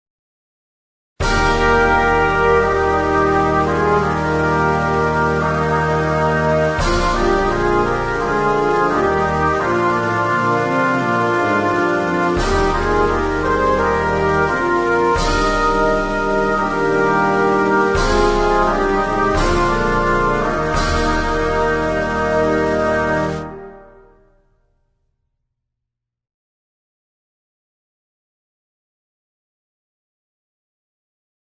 マーチングキーボード（幼児用）